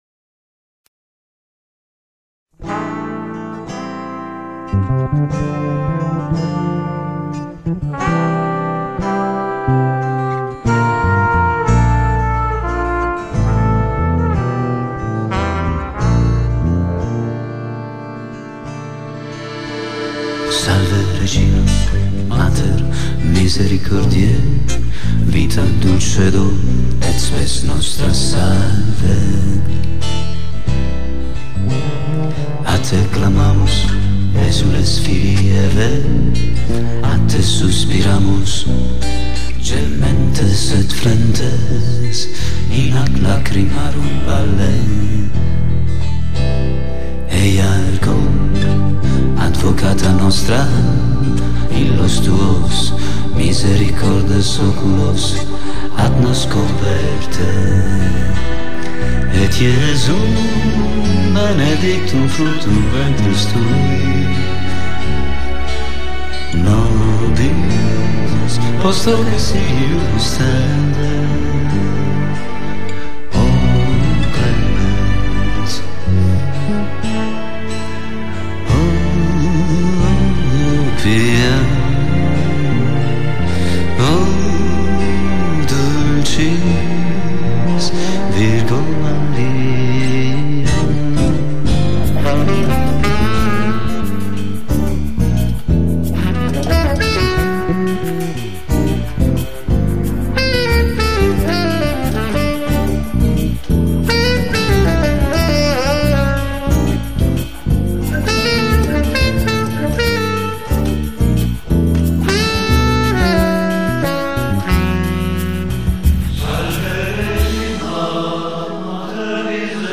Voce, chitarra, fisarmonica, flicorno soprano
Basso elettrico
Campionatura suoni e tastiere
Sassofono